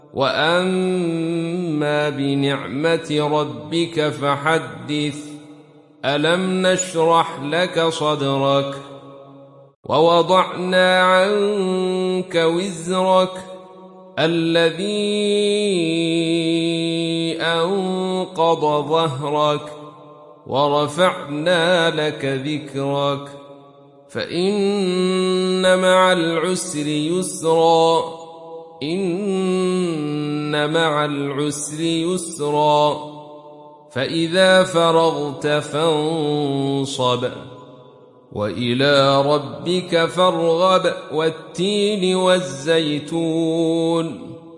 دانلود سوره الشرح mp3 عبد الرشيد صوفي روایت خلف از حمزة, قرآن را دانلود کنید و گوش کن mp3 ، لینک مستقیم کامل